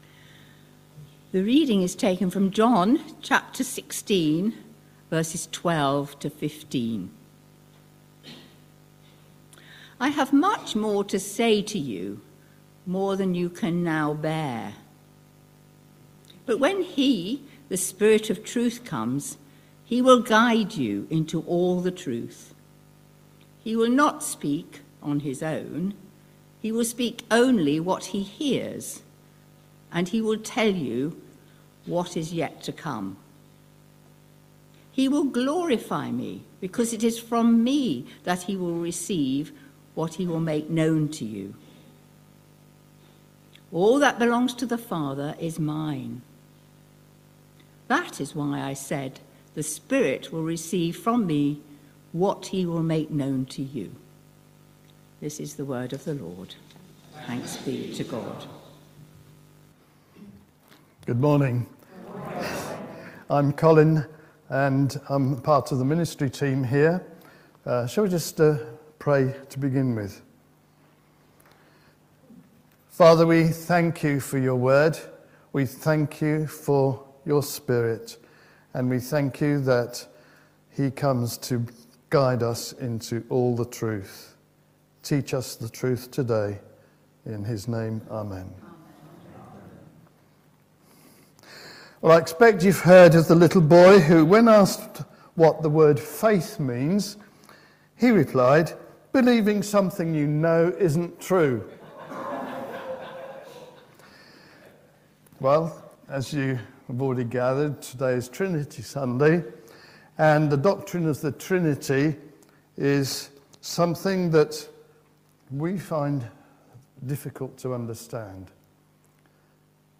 15th June 2025 Sunday Reading and Talk - St Luke's